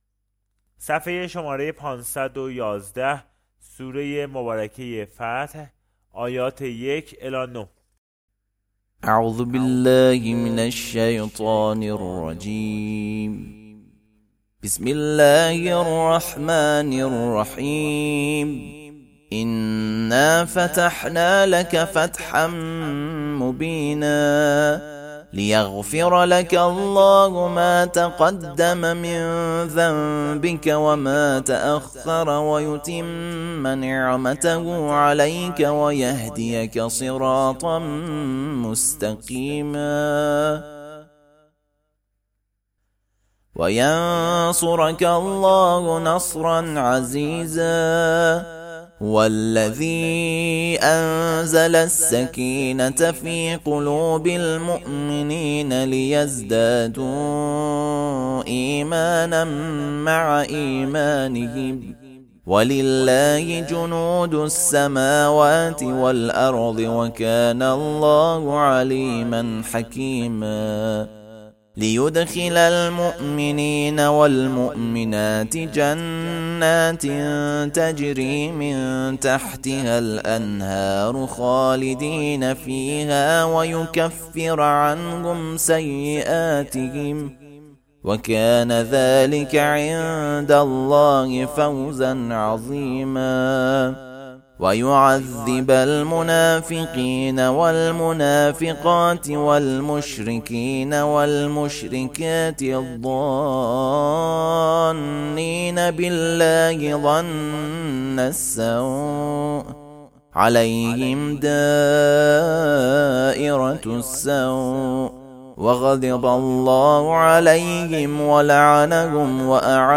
ترتیل سوره فتح